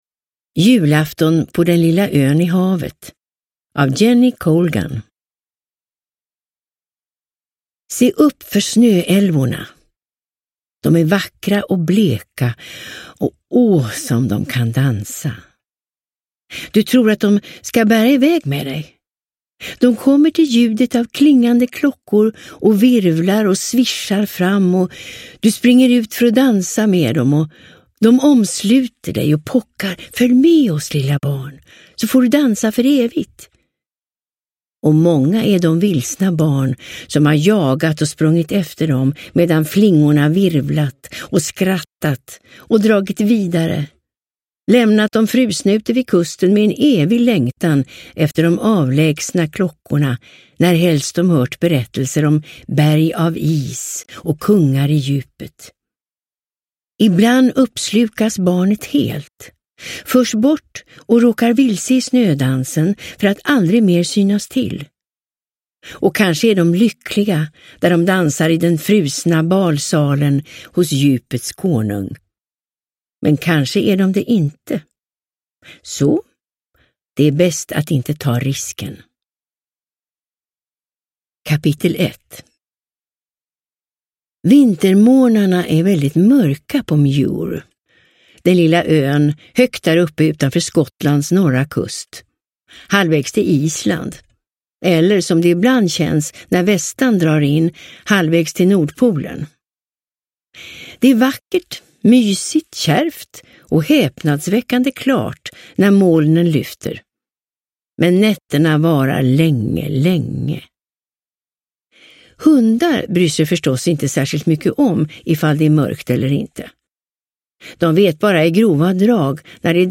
Julafton på den lilla ön i havet – Ljudbok – Laddas ner